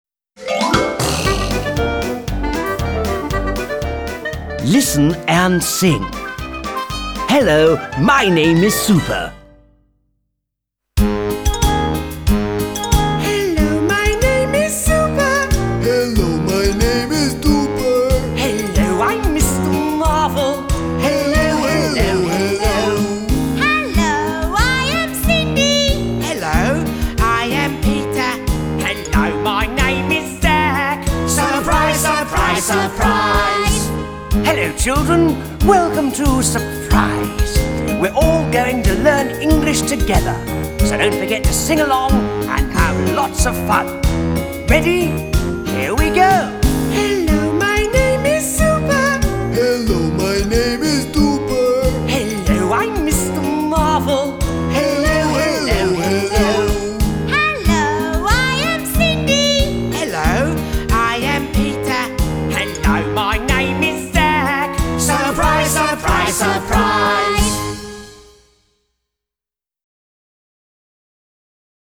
Звукозапись песни